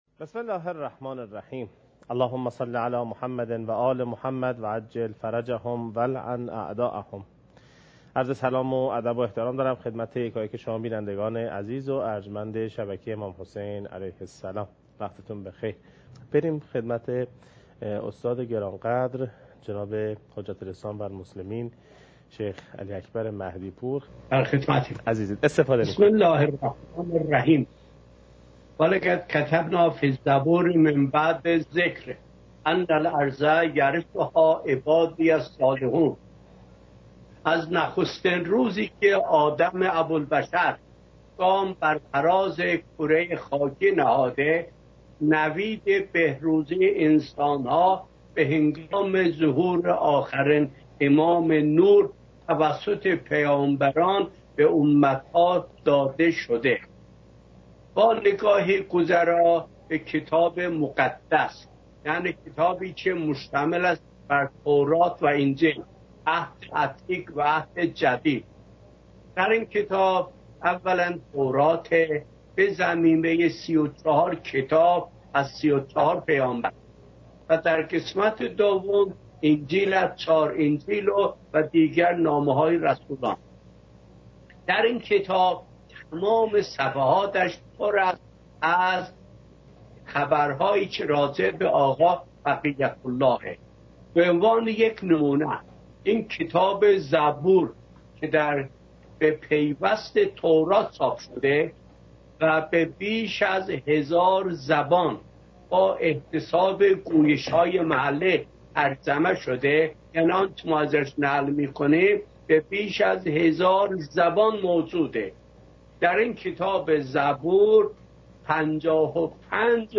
حجم: 9.97 MB | زمان: 42:15 | تاریخ: 1441هـ.ق | مکان: کربلا